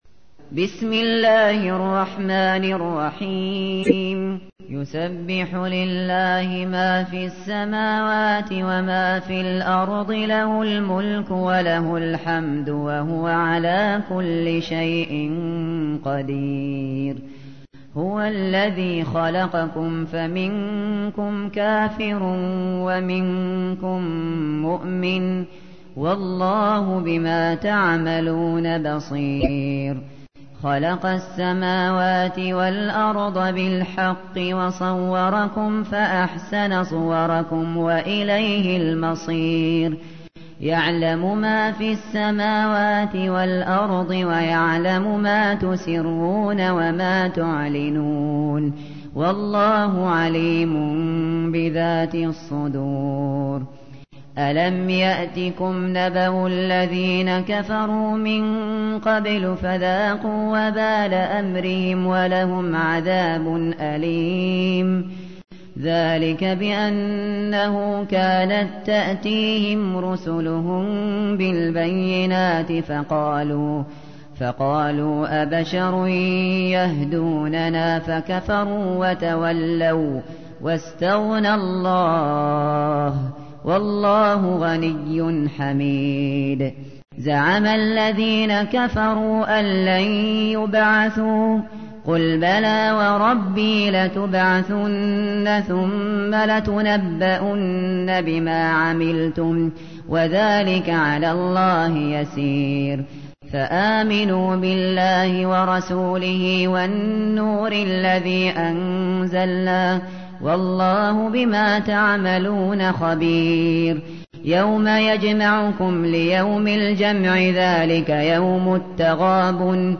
تحميل : 64. سورة التغابن / القارئ الشاطري / القرآن الكريم / موقع يا حسين